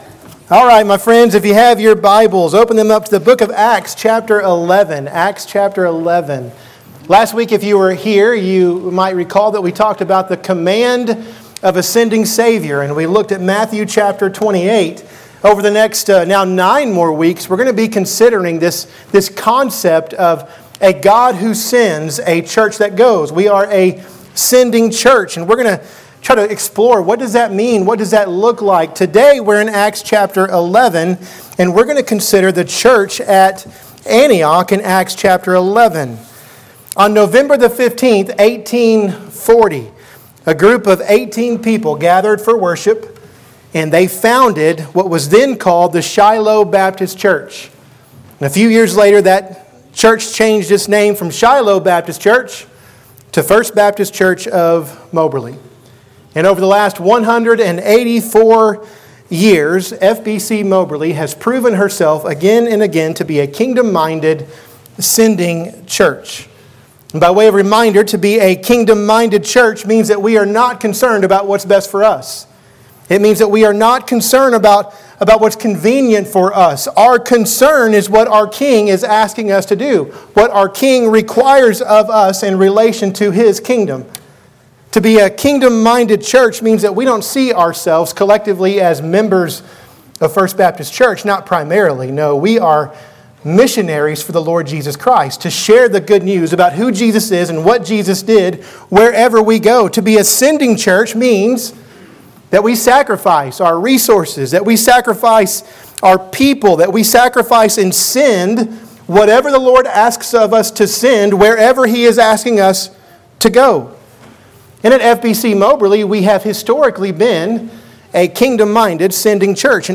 Sermons | First Baptist Church Moberly